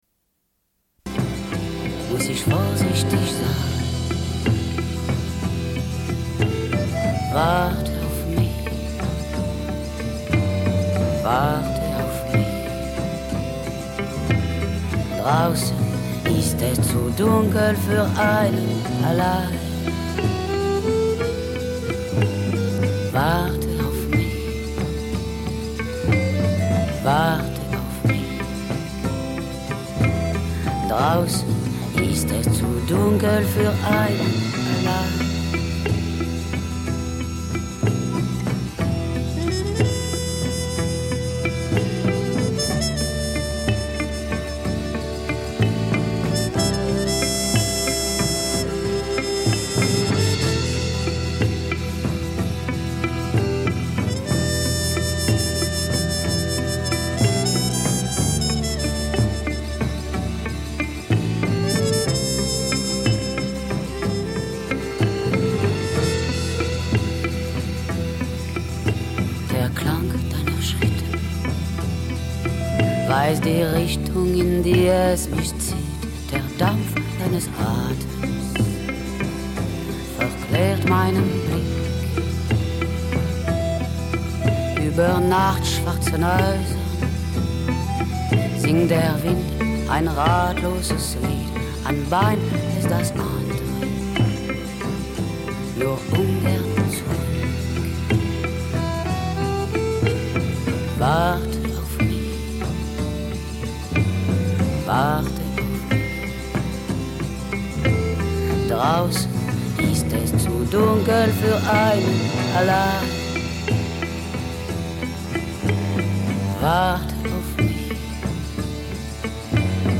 Une cassette audio, face A
Genre access points Radio Enregistrement sonore